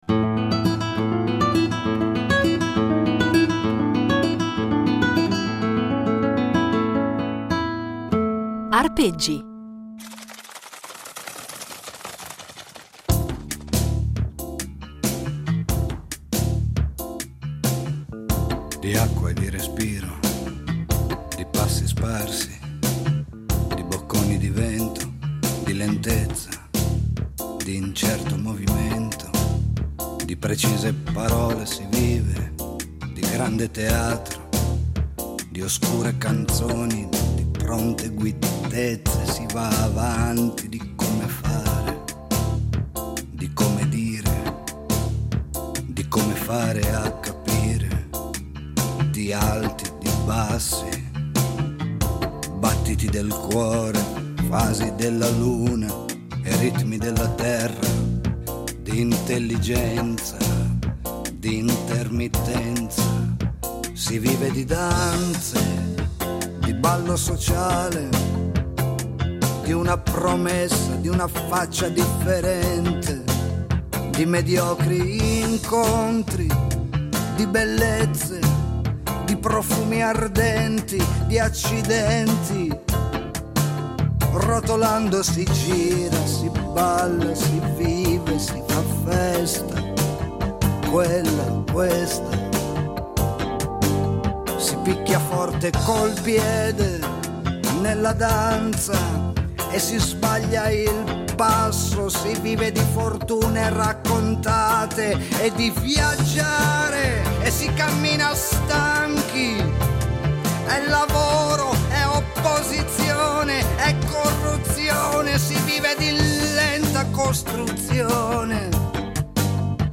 sax
chitarra